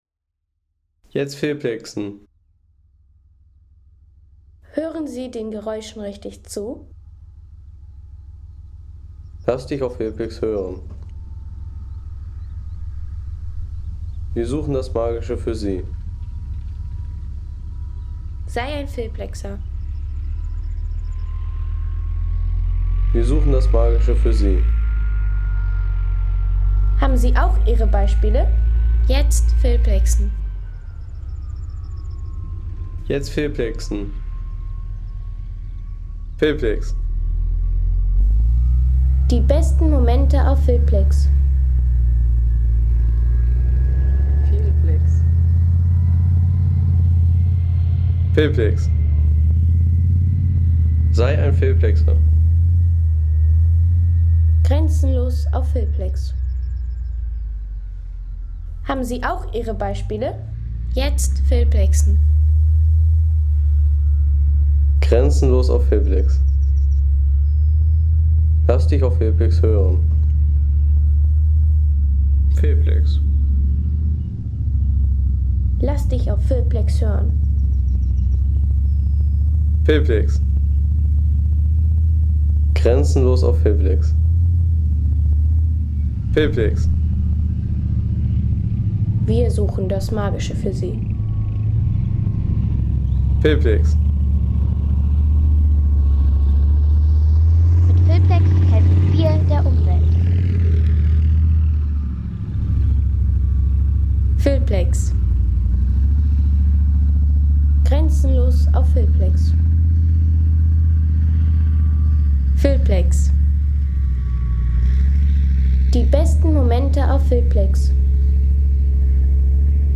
Kampfmaschine - Leopard 1A3